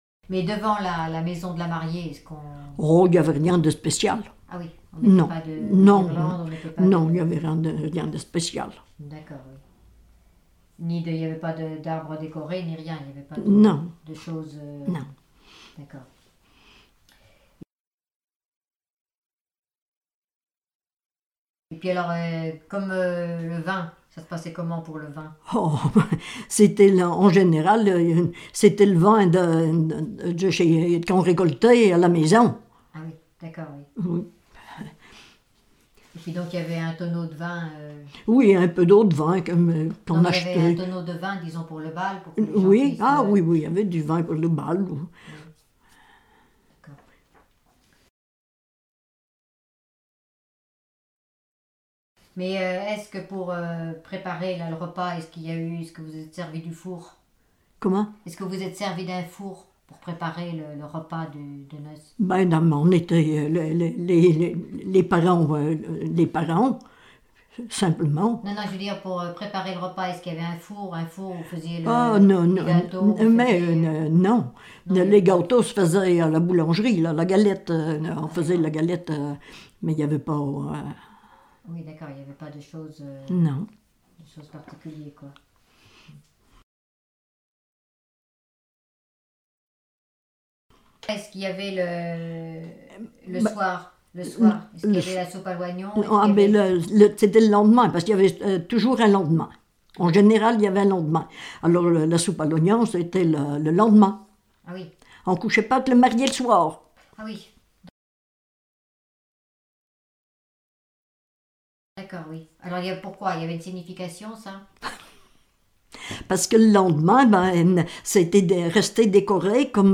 Enquête Arexcpo en Vendée-GAL Pays Sud-Vendée
Catégorie Témoignage